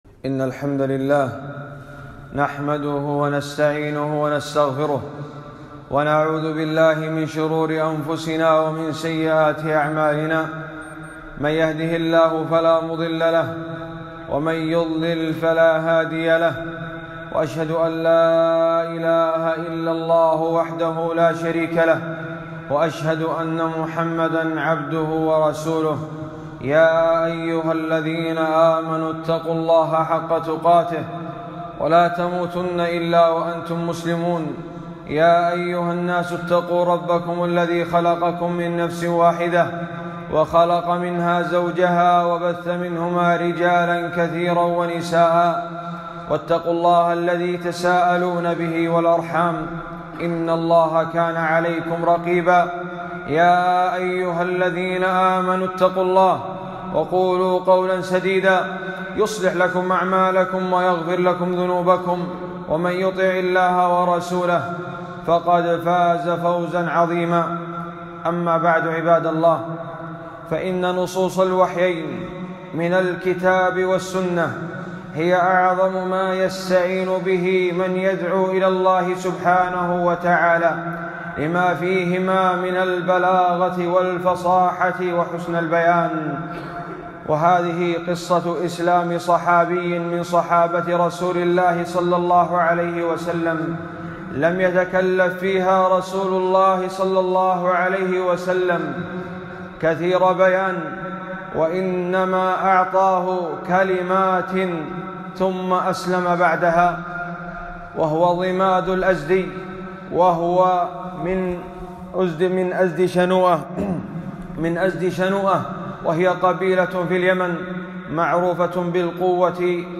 خطبة - قصة إسلام ضماد رضي الله عنه 9-6-1442